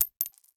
household
Coin Falling on Floor